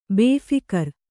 ♪ bēphikar